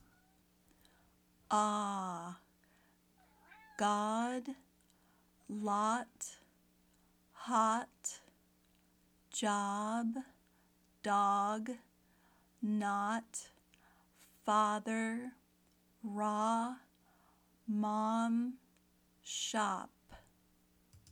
Practice the Short “O” Sound
short-o.mp3